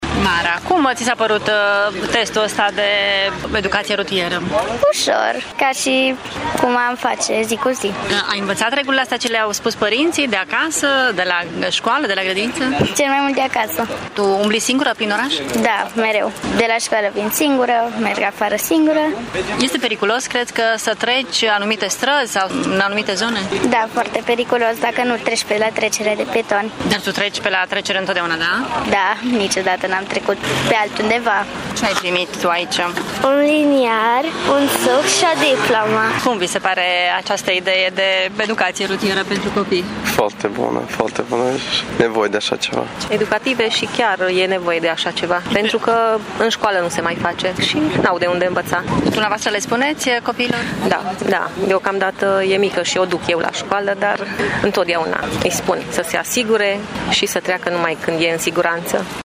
Părinții cred că astfel de lecții venite direct de la polițiști sunt deosebit de educative: